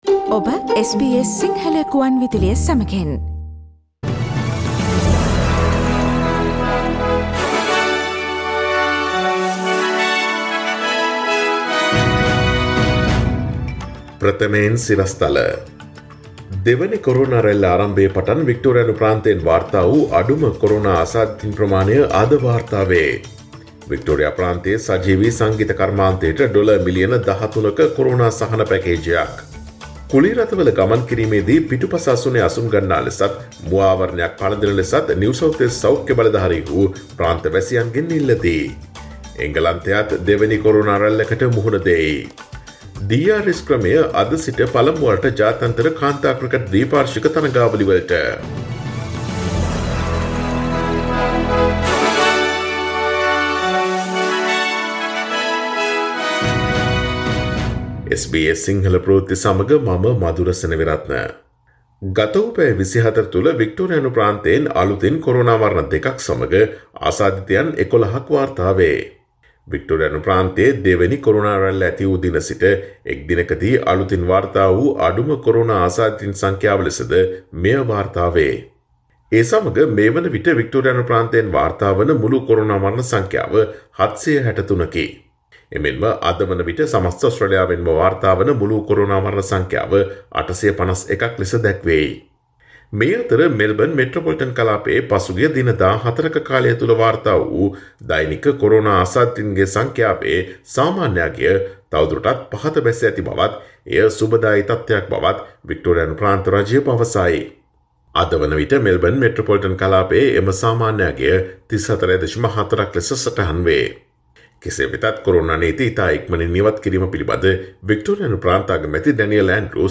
Daily News bulletin of SBS Sinhala Service: Monday 21 September 2020